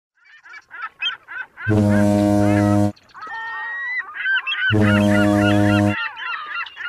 دانلود آهنگ بوق کشتی 3 از افکت صوتی حمل و نقل
دانلود صدای بوق کشتی 3 از ساعد نیوز با لینک مستقیم و کیفیت بالا
جلوه های صوتی